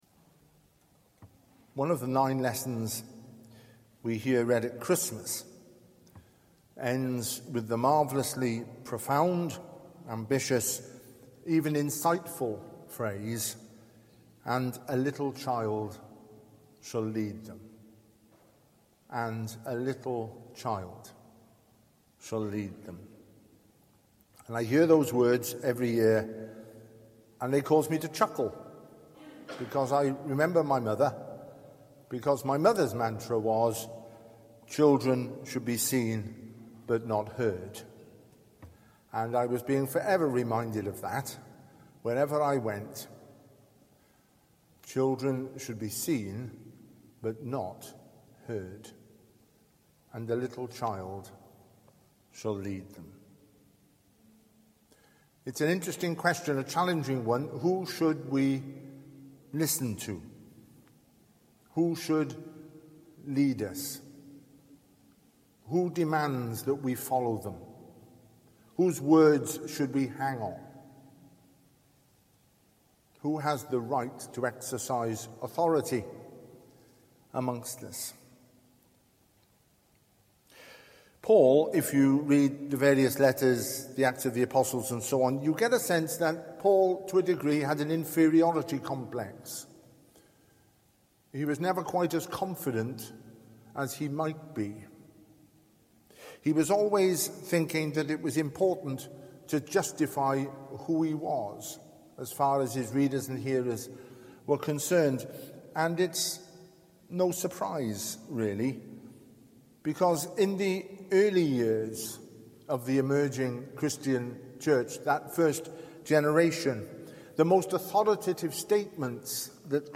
Service Type: Family Service